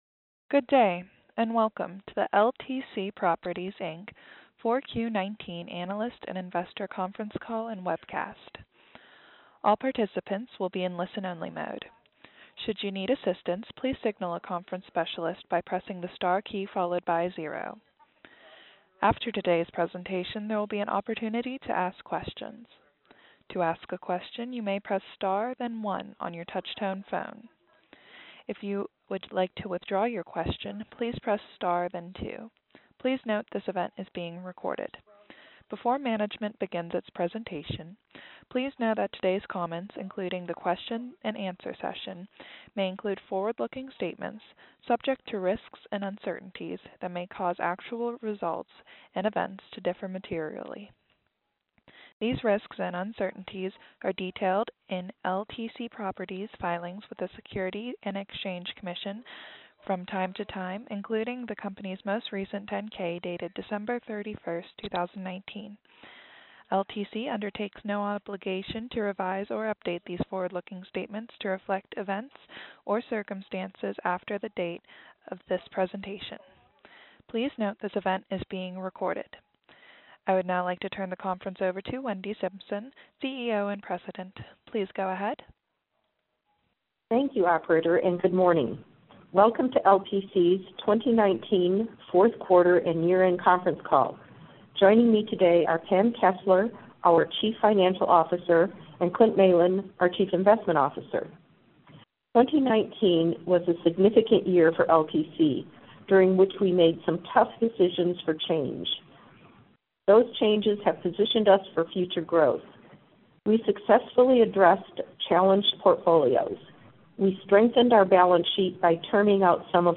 Audio-Replay-of-LTC-Properties-Inc-Q4-2019-Earnings-Call.mp3